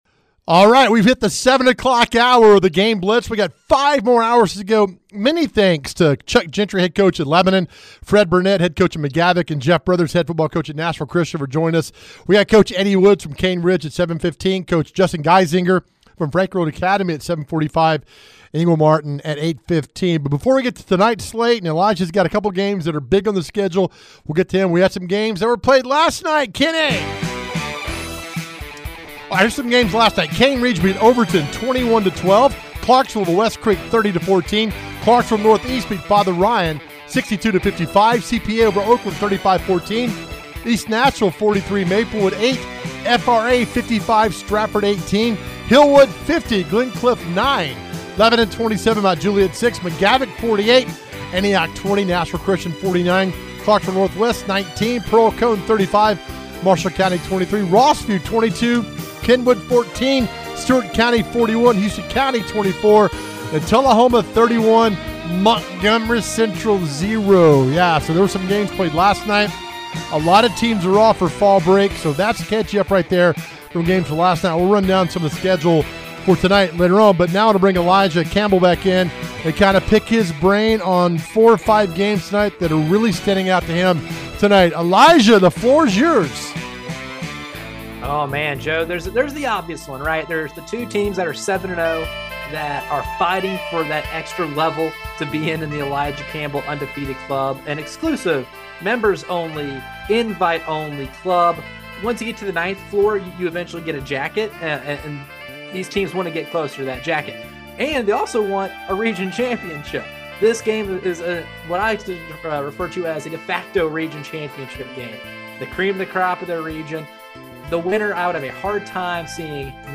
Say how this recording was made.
They bring you six hours of everything in the world of Middle TN High School Football. We have interviews with coaches and reporters live at the hottest games!